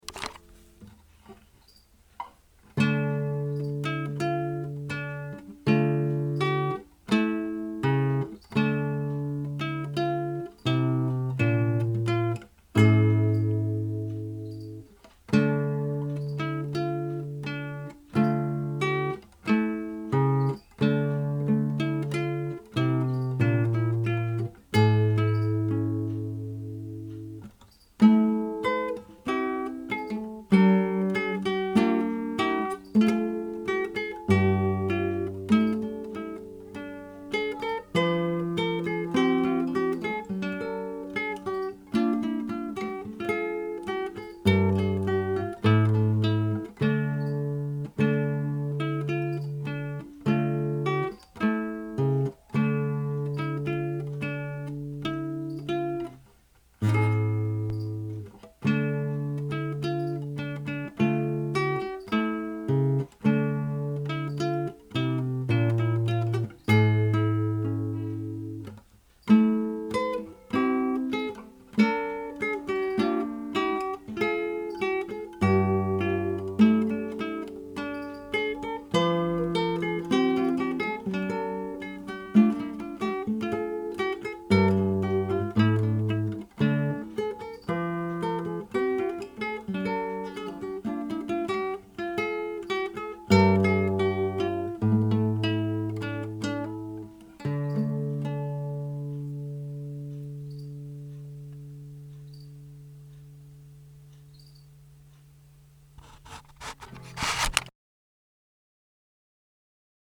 My focus is on music by John Dowland from the late 1500s/early 1600s, originally composed for the lute and carefully adapted for classical guitar.
Renaissance Period